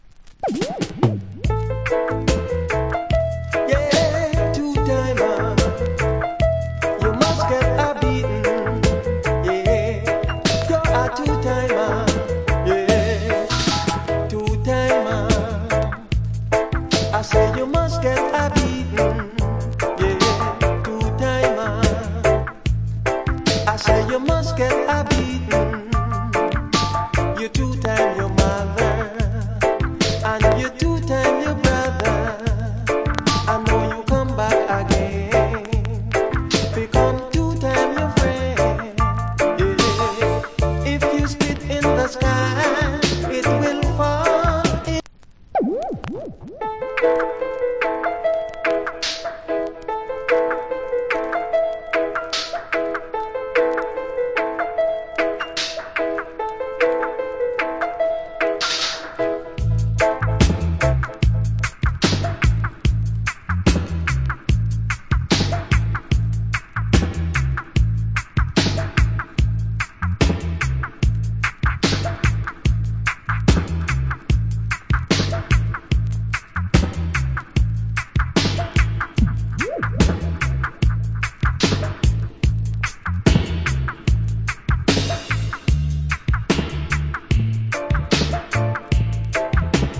Killer Reggae Vocal.